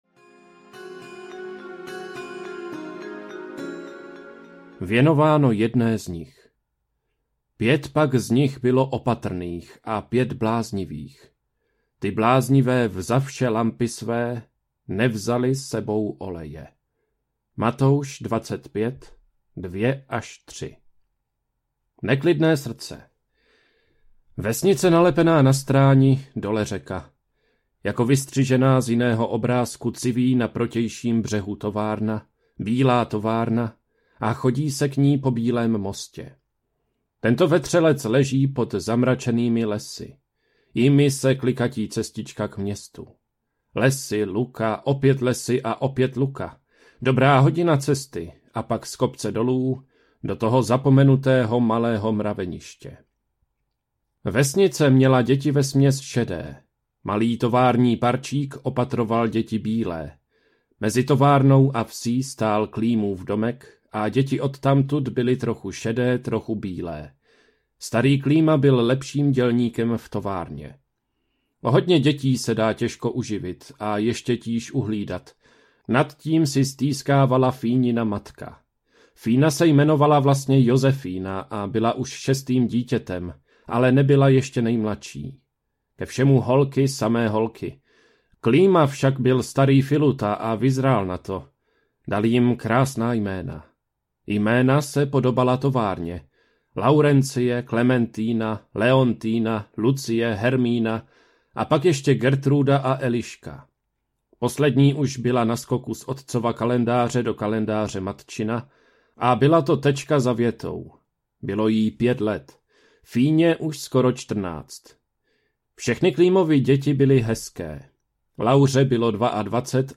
Neopatrné panny audiokniha
Ukázka z knihy